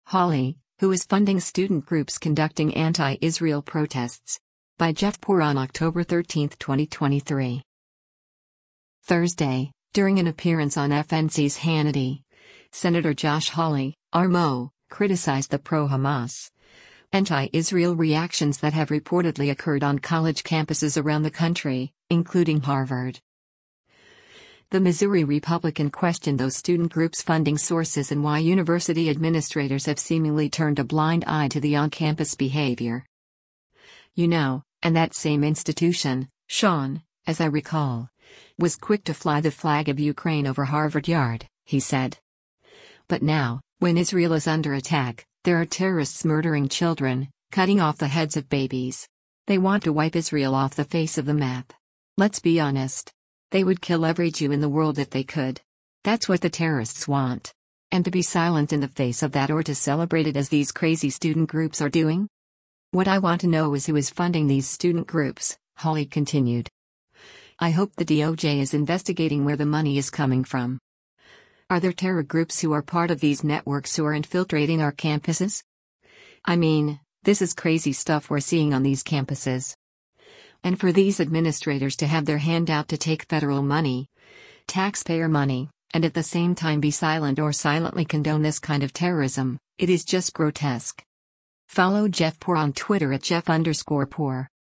Thursday, during an appearance on FNC’s “Hannity,” Sen. Josh Hawley (R-MO) criticized the pro-Hamas, anti-Israel reactions that have reportedly occurred on college campuses around the country, including Harvard.